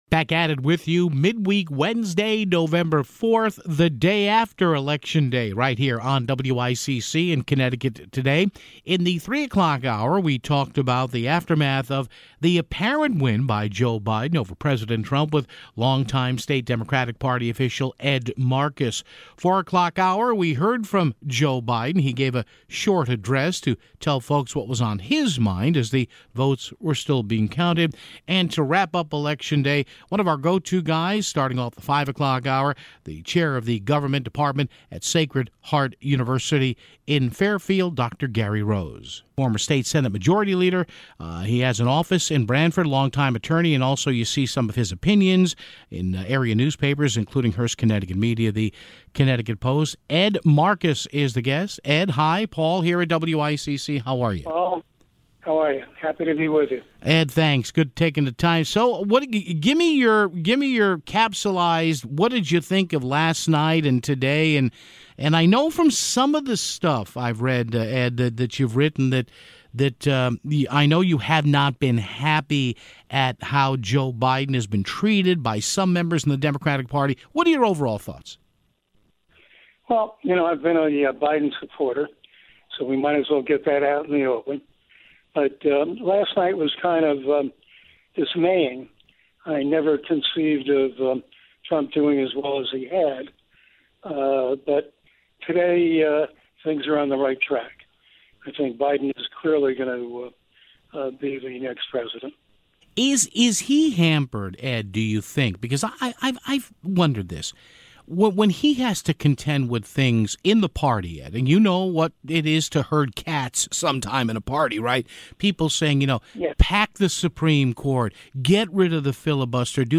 Vice President and Apparent President Elect Joe Biden gave an address to media today (11:56).